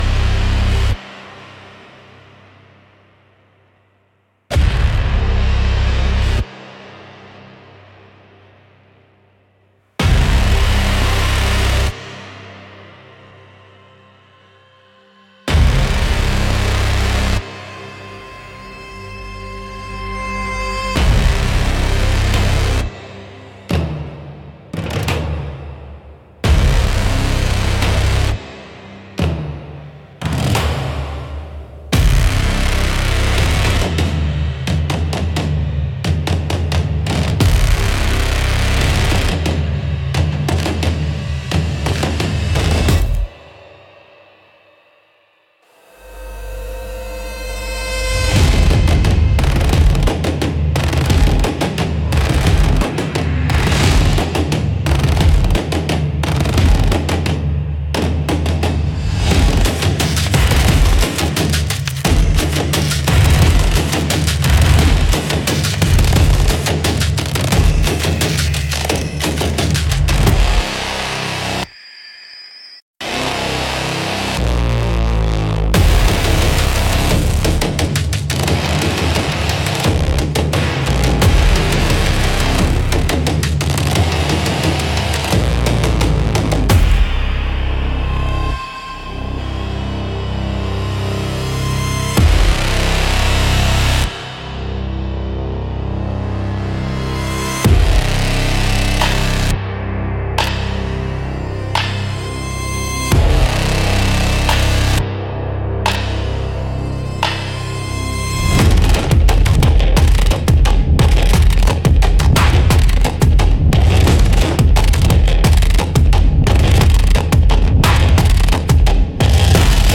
Instrumental - Resonance Protocol -3.17 - Grimnir Radio